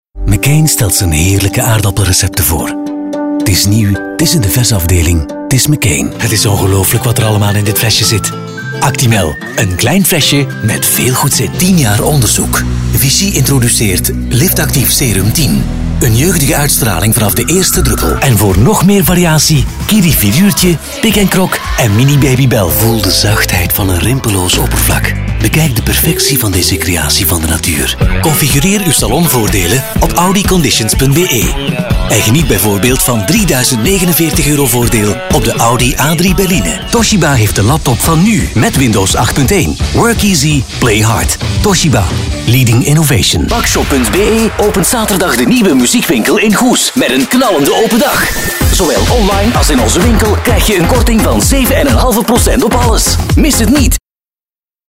Allround Flemish voice over, wide range of styles
Sprechprobe: Werbung (Muttersprache):
Allround voice over with recording studio / Live direction via Source Connect / Skype